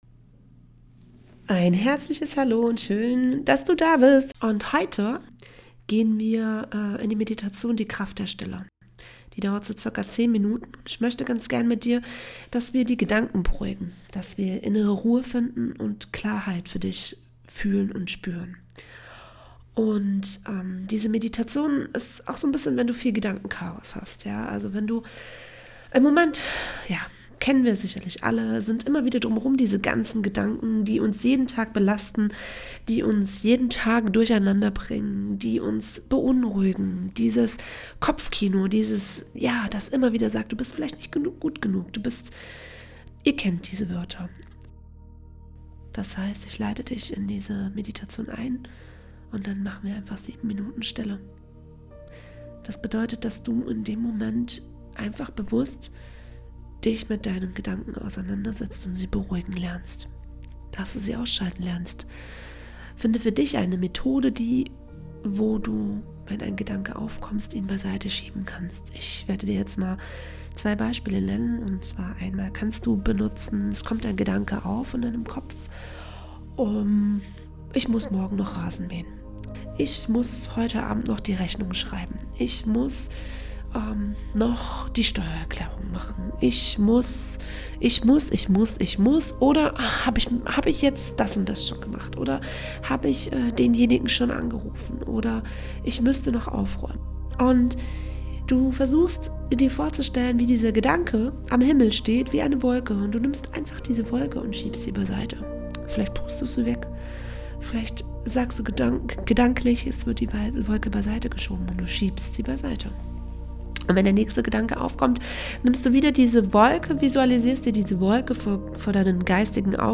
Was dich erwartet: Eine kurze Einführung zur Vorbereitung 7 Minuten reine Stille Wie du die Meditation nutzen kannst: Finde einen ruhigen Ort, an dem du ungestört bist Setze oder lege dich bequem hin Schließe die Augen und lass die Stille auf dich wirken Willkommen auf meinem Podcastkanal für Heilung, Transformation & Bewusstseinserweiterung!